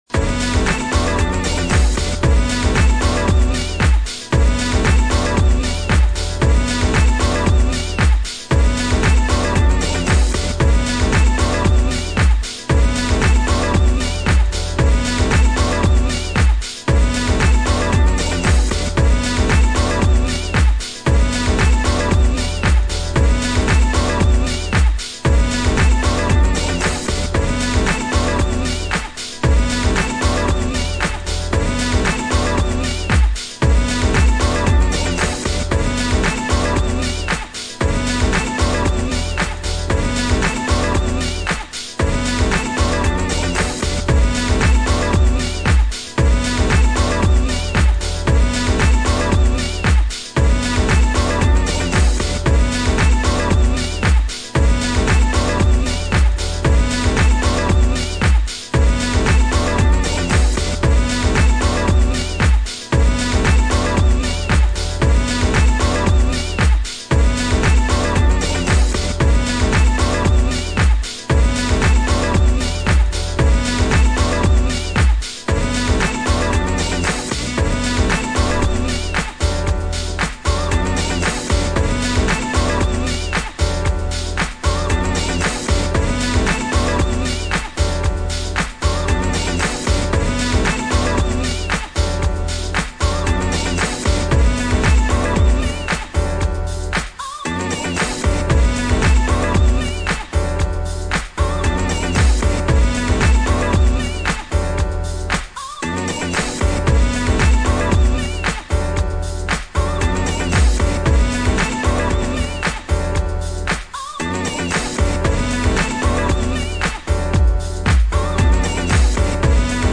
ジャズ・ファンキッシュ・ハウス傑作！！